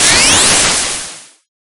Wind2.ogg